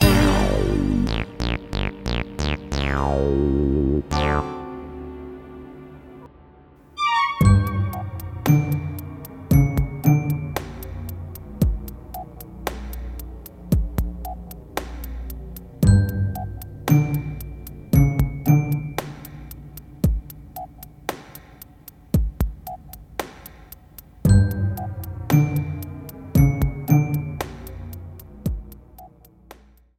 Trimmed and fadeout
This is a sample from a copyrighted musical recording.